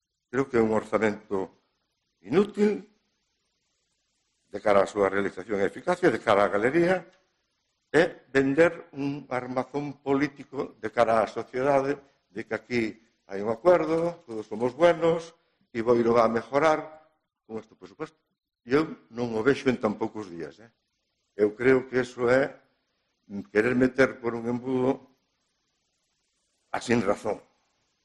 Intervención de Juan José Dieste, portavoz del PP